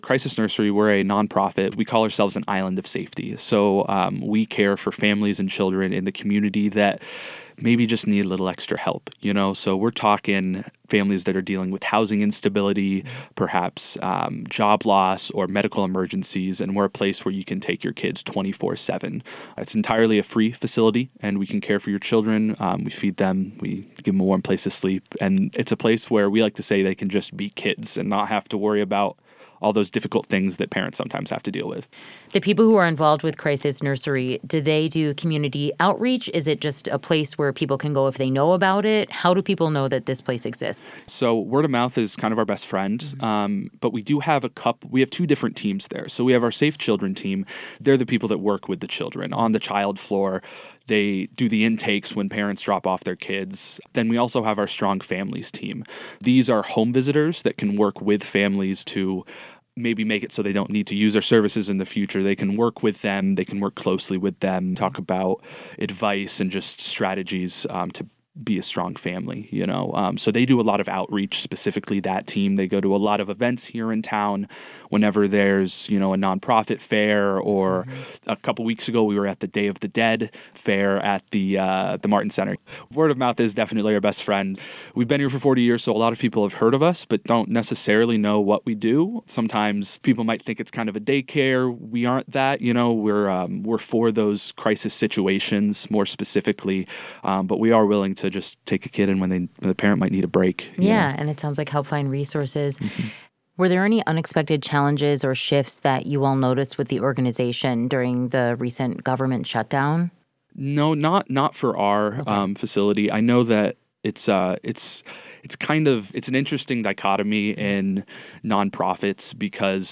This interview has been edited for clarity and conciseness.